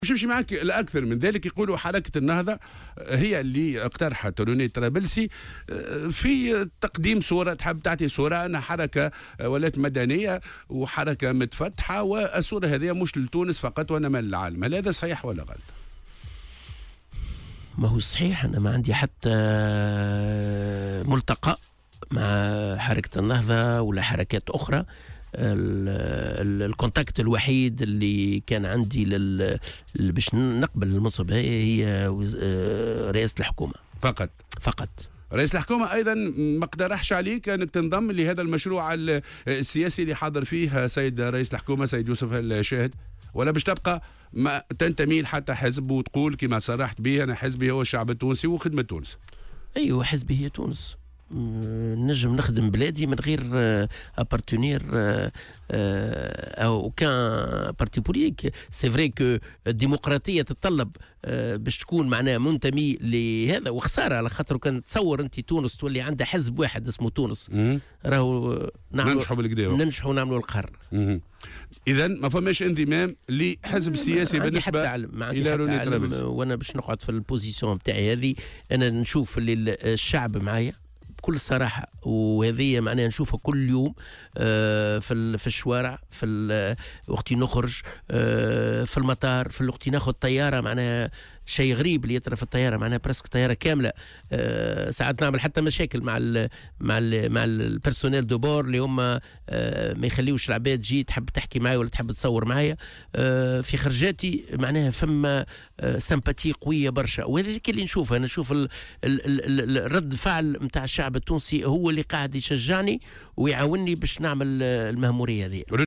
وأكد في حوار مع "الجوهرة أف أم" اليوم الأربعاء، أن رئاسة الحكومة هي الجهة التي عرضت عليه هذا المنصب و أنه ليس له أي علاقة بأي جهة سياسية.